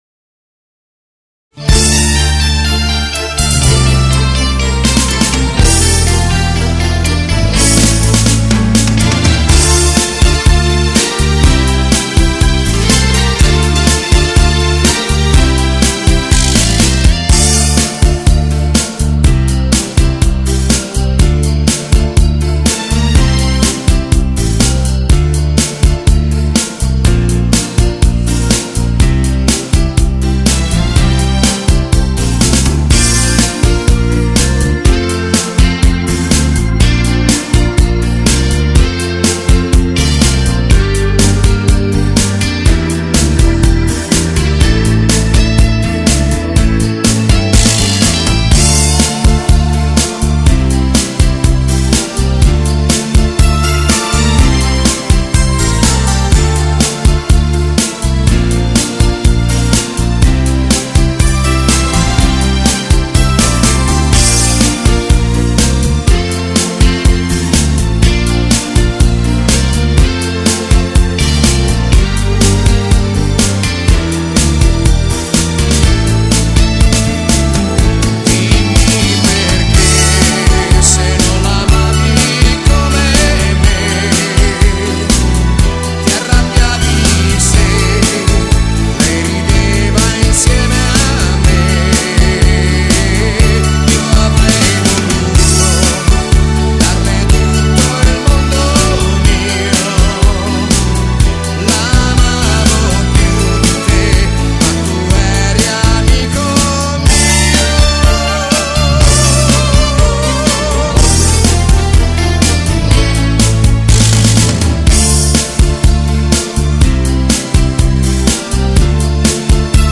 Genere: Lento
Scarica la Base Mp3 (3,48 MB)